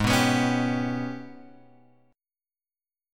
G# Minor Major 11th